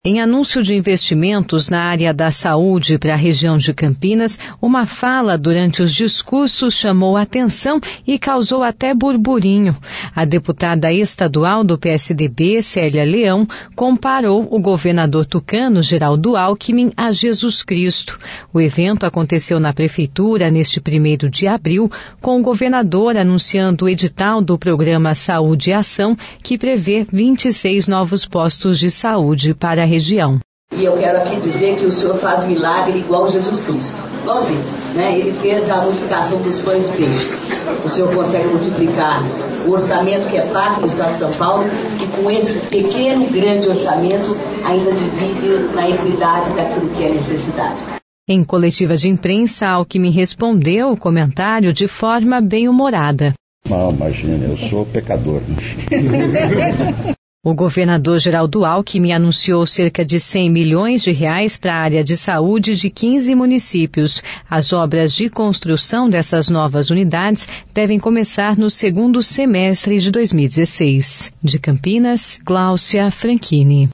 Em evento em Campinas, deputada tucana compara Alckmin a Jesus Cristo
Em anúncio de investimentos na área da saúde para região de Campinas, uma fala durante os discursos chamou atenção e causou até burburinho. A deputada estadual do PSDB, Célia Leão, comparou o governador tucano, Geraldo Alckmin, a Jesus Cristo.
Em coletiva de imprensa, Alckmin respondeu o comentário de forma bem humorada.